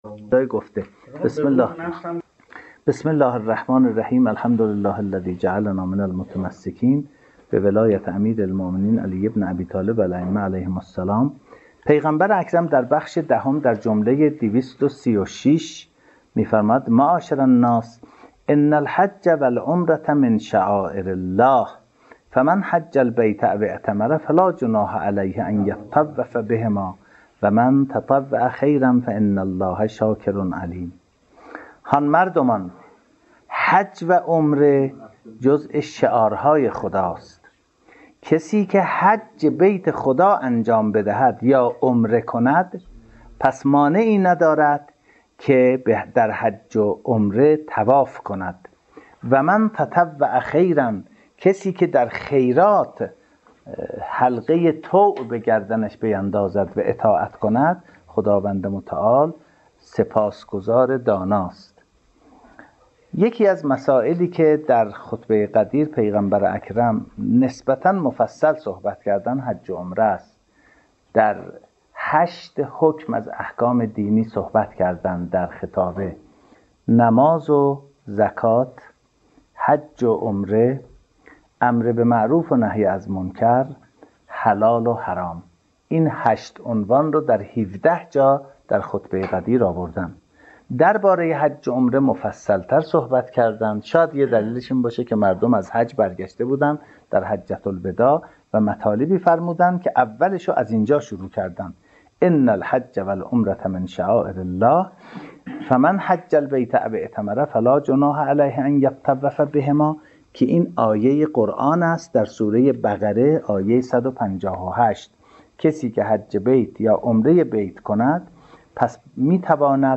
آرشیو سخنرانی‌های خطابه‌ی غدیر